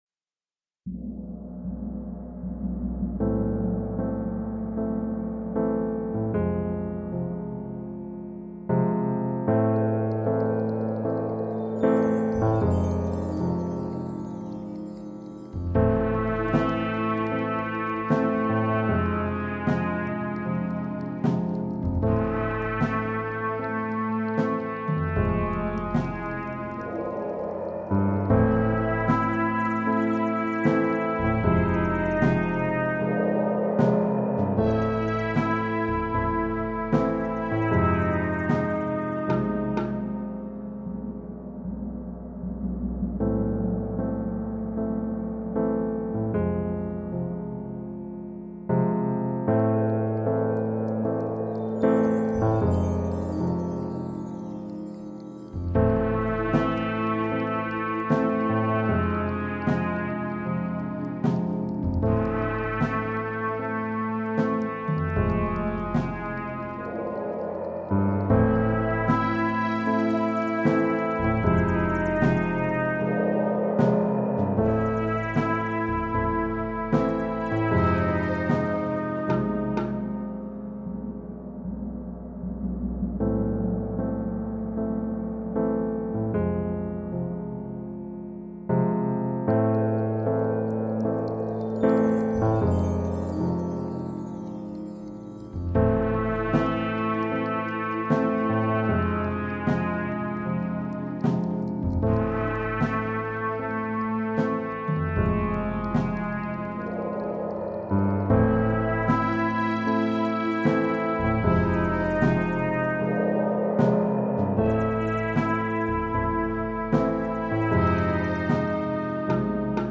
Alle zwei Minuten ertönt das Horn.